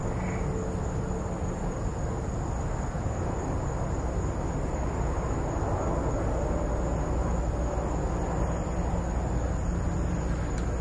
生物 " 青蛙3
描述：用奥林巴斯DS40与索尼ECMDS70P记录的夜间的青蛙和昆虫。
Tag: 场记录 青蛙 昆虫